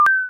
bling5.mp3